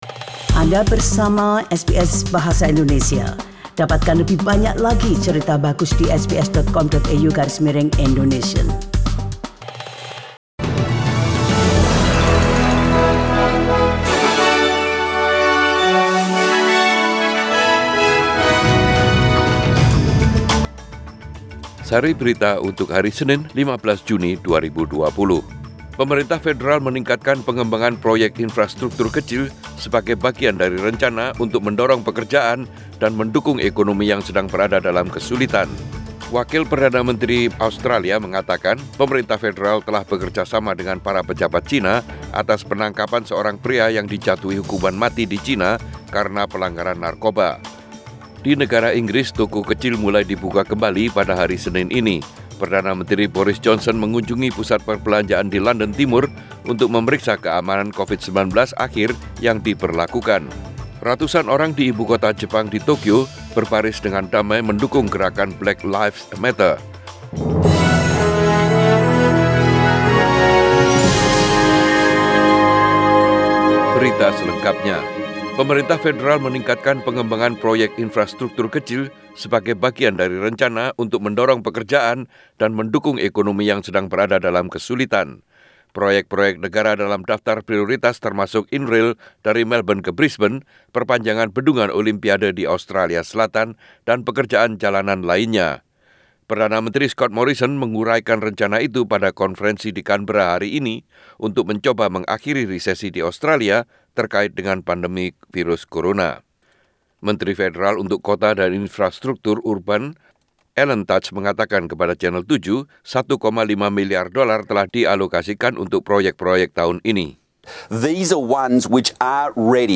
SBS Radio News in Bahasa Indonesia - 15 June 2020
Warta Berita Radio SBS Program Bahasa Indonesia Source: SBS